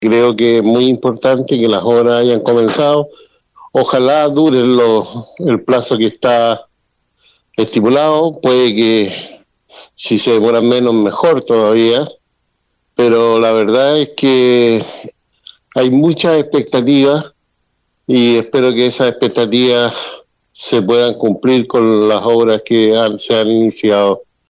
Por su parte, el concejal y presidente de la Comisión de Patrimonio, Dante Iturrieta, espera que este proyecto, en el cual las expectativas son altas, se entregue dentro de los plazos ya mencionados.